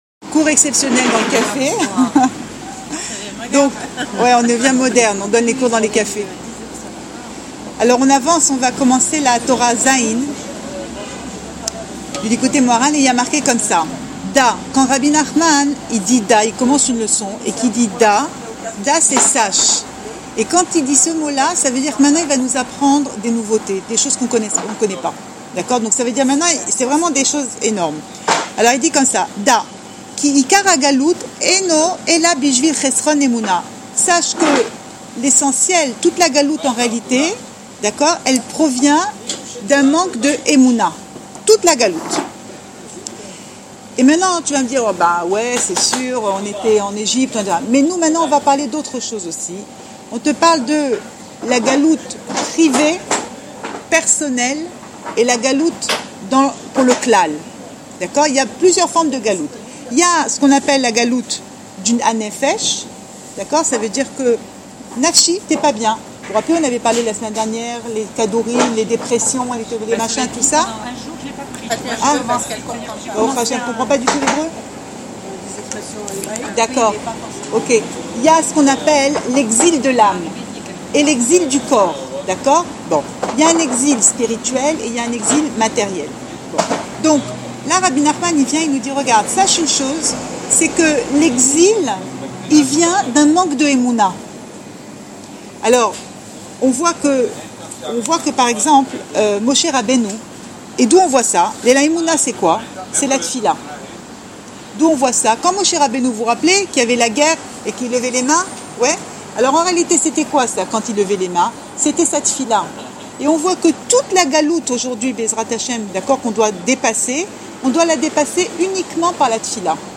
Torah zain – Likoutei Moharan Cours audio Le coin des femmes Pensée Breslev - 17 février 2015 20 juillet 2015 Torah zain – Likoutei Moharan Enregistré à Raanana Share on Facebook Share Share on Twitter Tweet